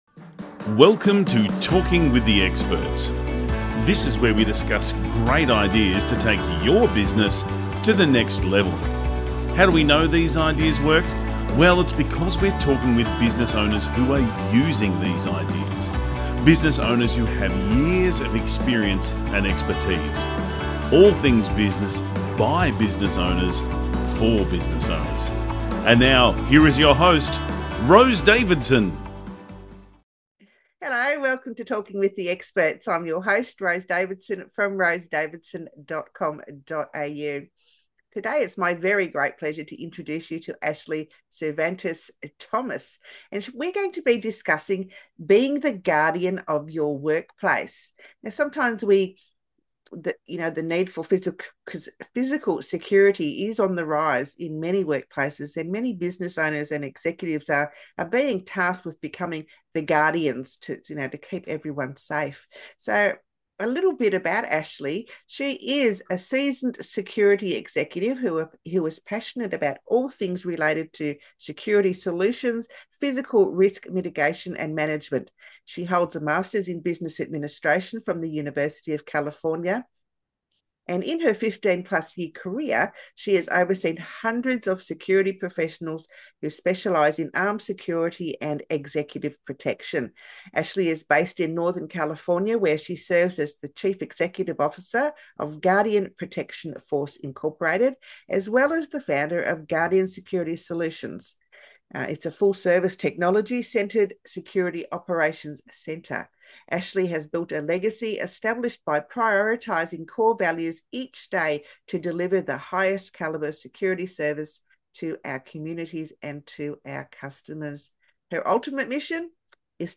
THREE Key Points from the Interview: